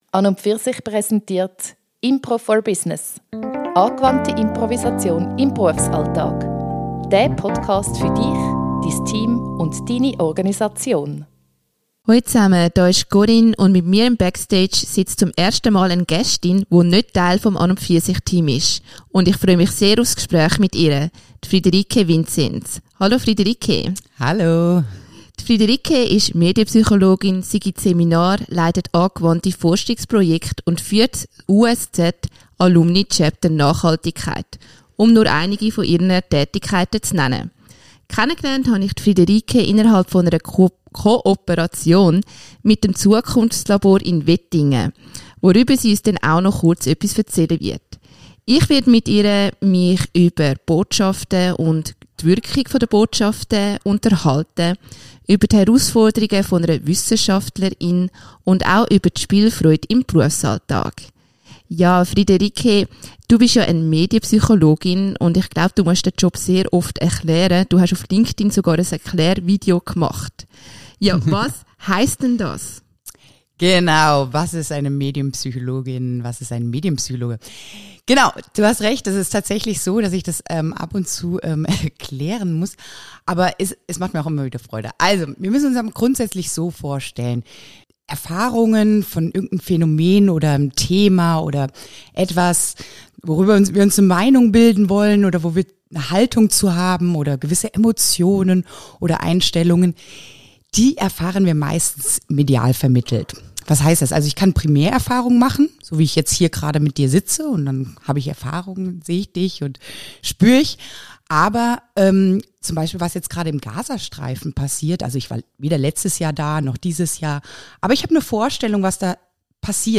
im Interview.